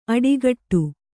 ♪ aḍigaṭṭu